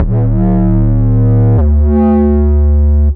bass.wav